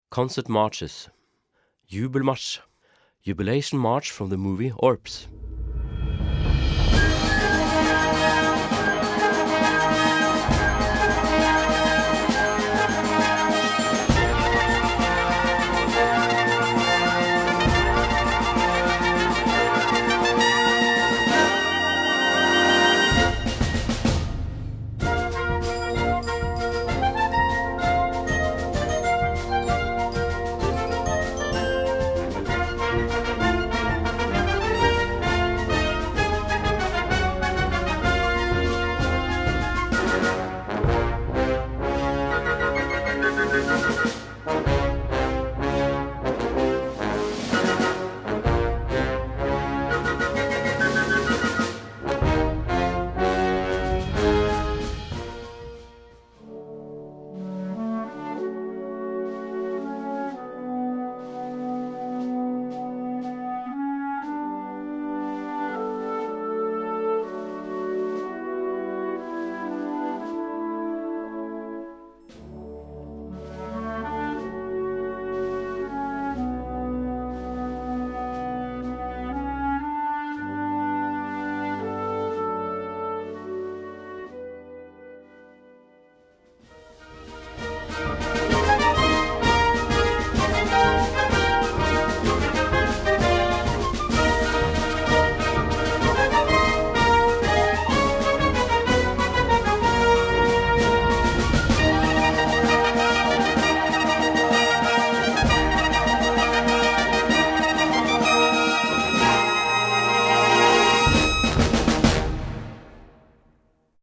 C Besetzung: Blasorchester PDF